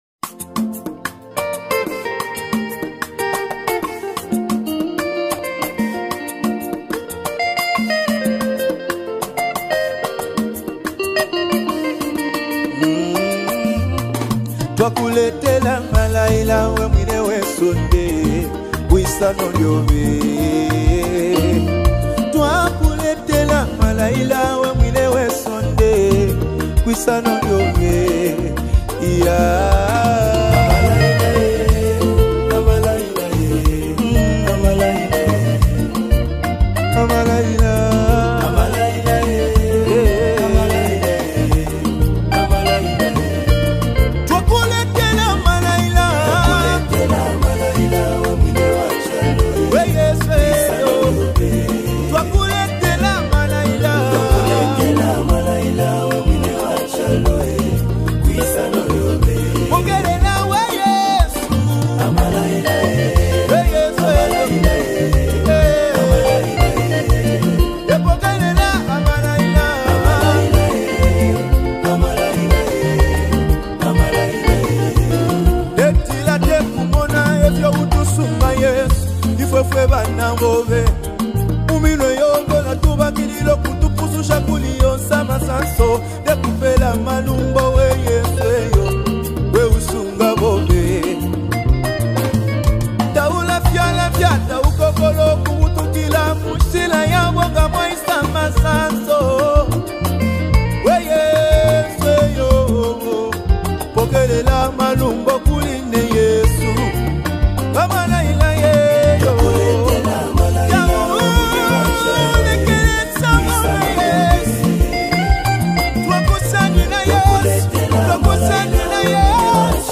Gospel music minister